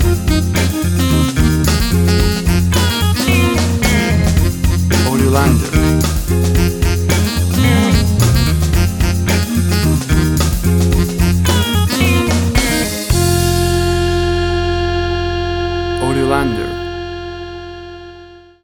Tempo (BPM): 110